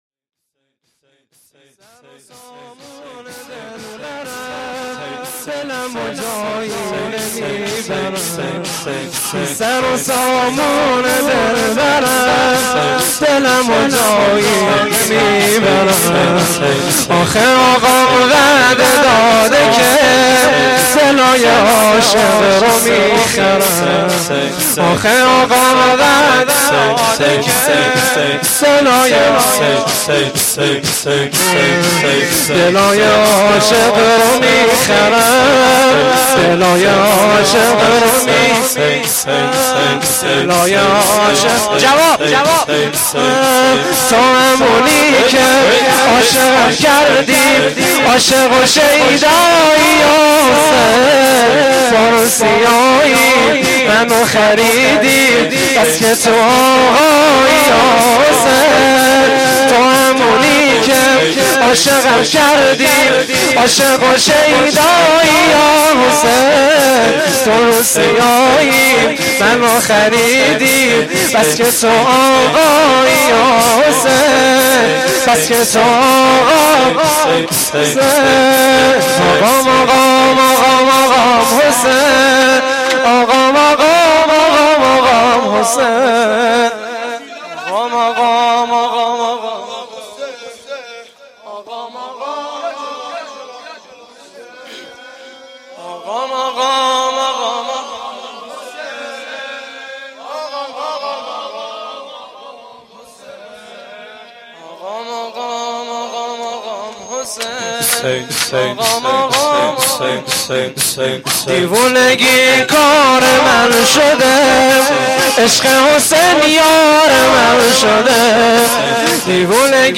شور
روضه العباس